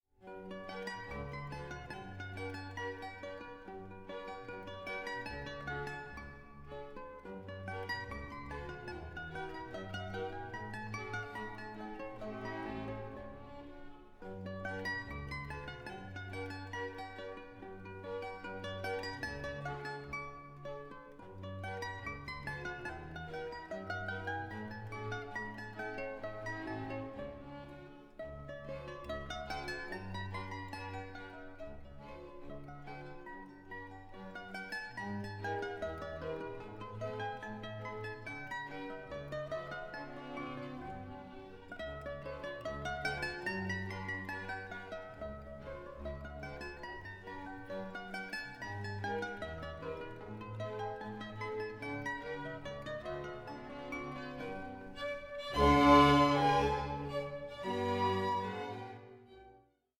works for mandolin and orchestra